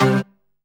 ORGAN-26.wav